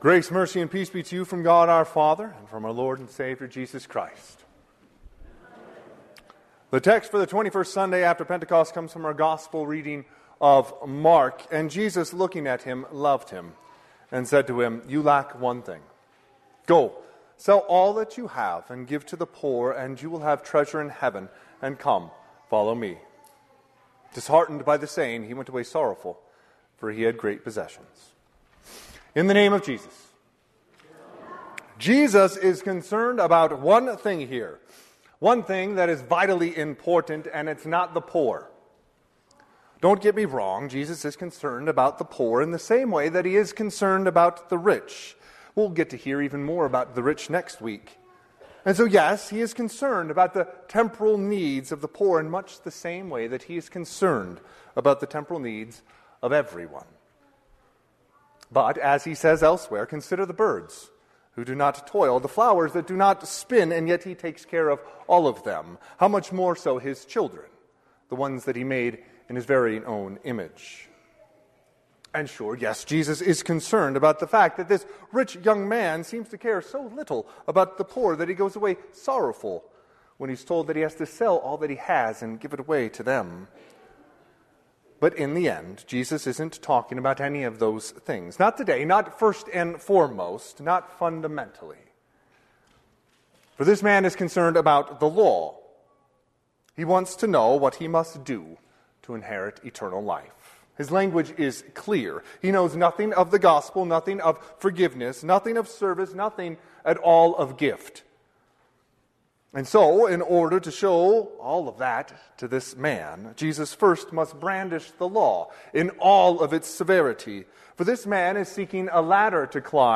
Sermon - 10/13/2024 - Wheat Ridge Lutheran Church, Wheat Ridge, Colorado
Twenty-first Sunday of Pentecost